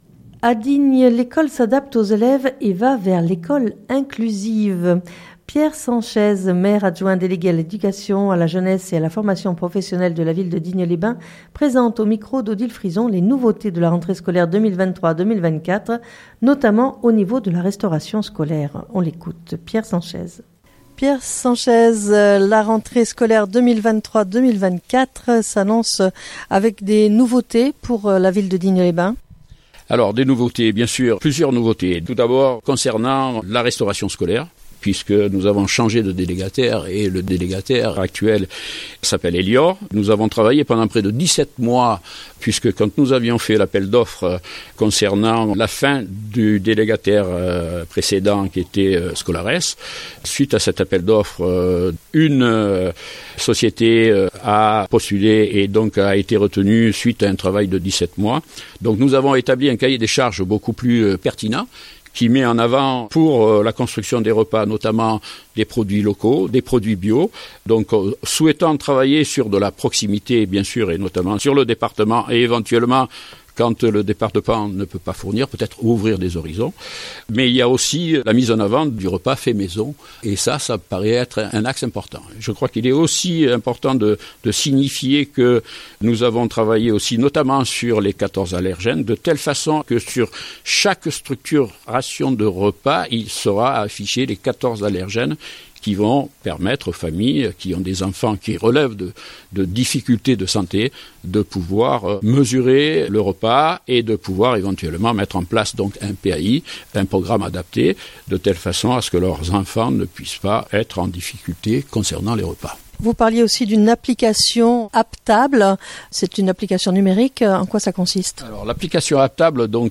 Pierre Sanchez Maire adjoint délégué à l'éducation, à la jeunesse, et à la formation professionnelle de la ville de Digne les bains présente au micro